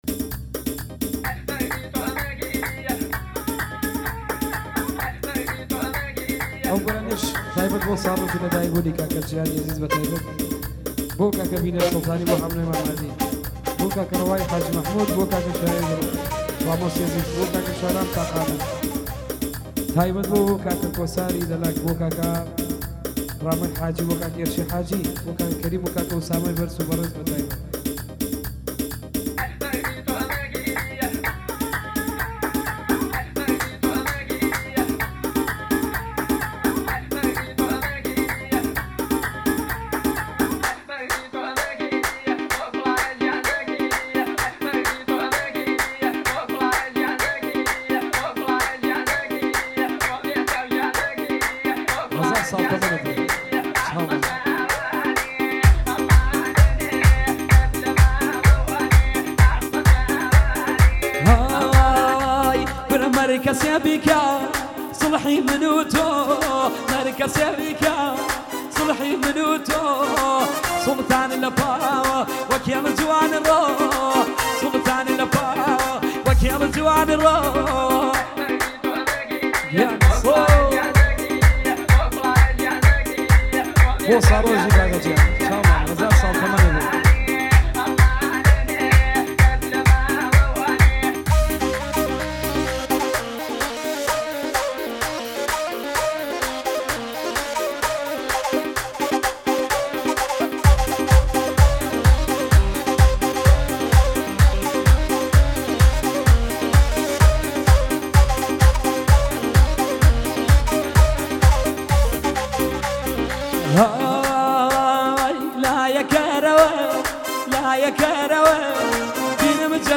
آهنگ محلی